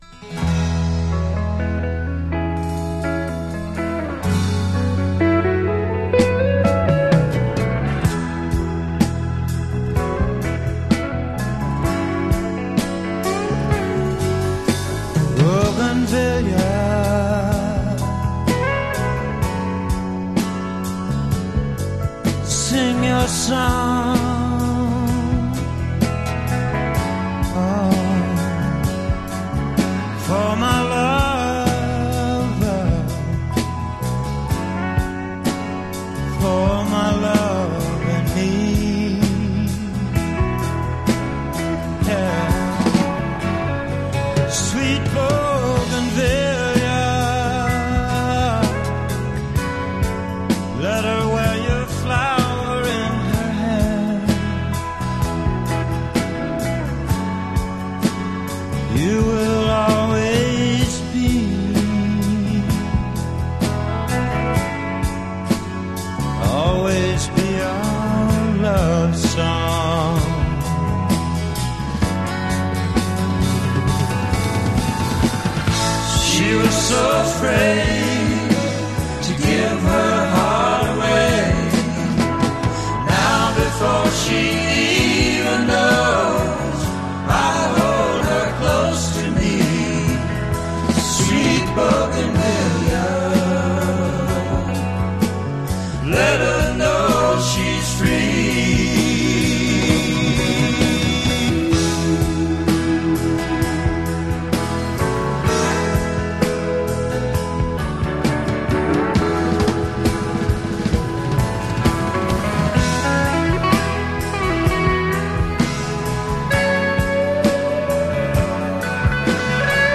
It has Mint labels and pristine sound.